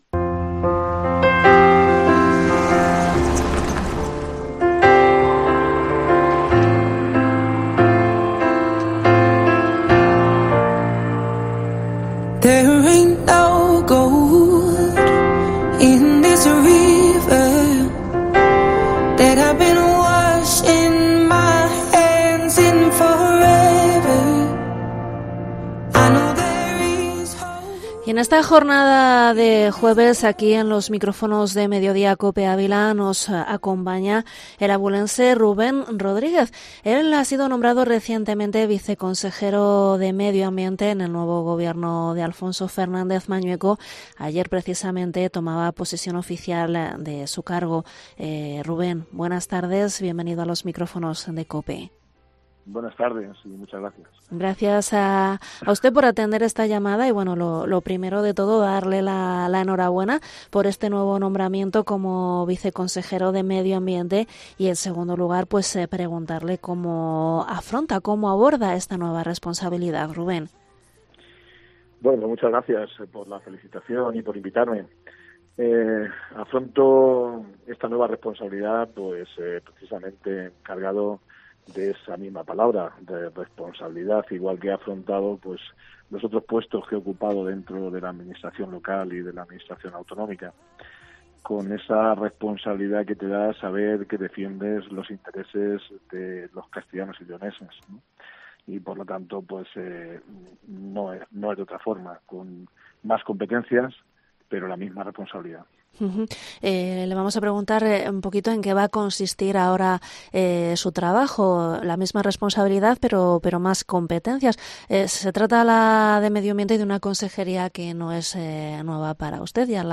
Entrevista Rubén Rodríguez, viceconsejero Medio Ambiente